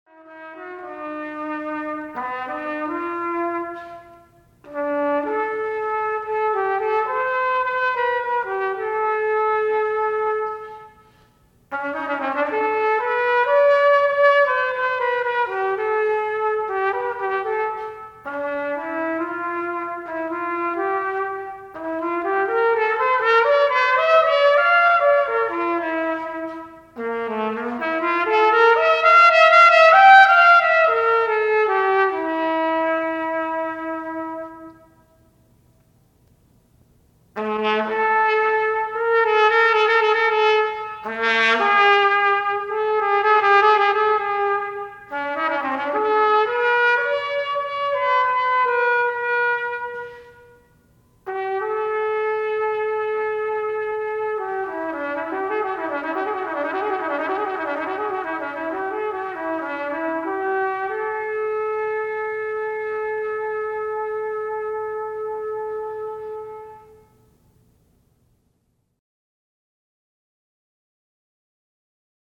for Bb Trumpet Unaccompanied
Introspectively